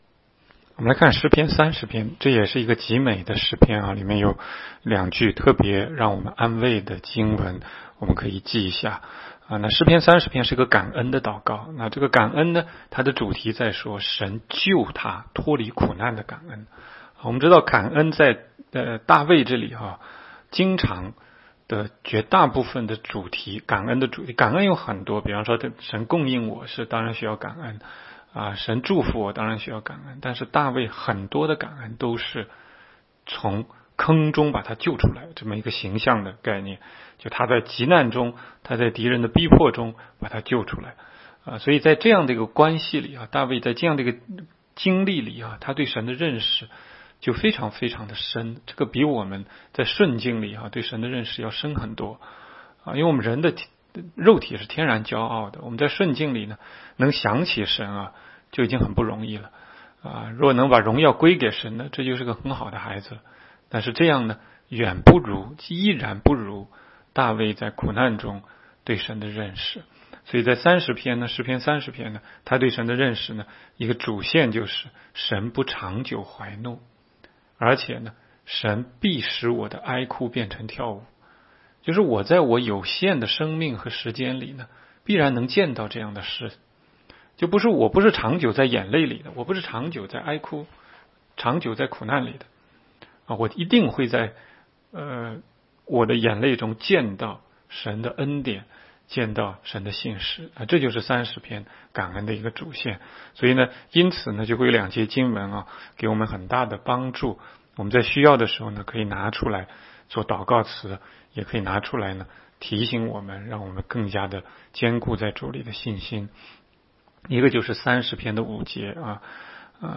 16街讲道录音 - 每日读经-《诗篇》30章